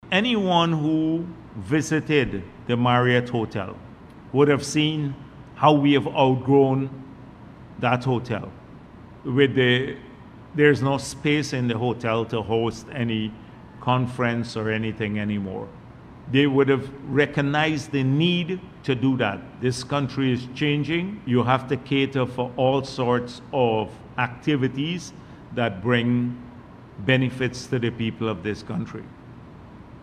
During a recent press conference, Dr. Jagdeo addressed concerns about the project’s development, expressing confidence in its progress.